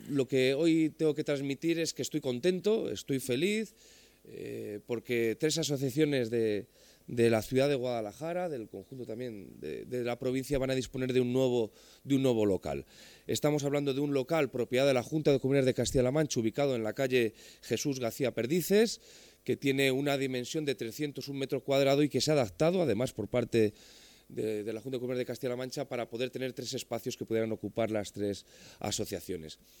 El delegado de la Junta en Guadalajara, Alberto Rojo, habla de la cesión de locales por parte del Gobierno regional a tres entidades sociales.